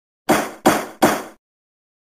Gavel Banging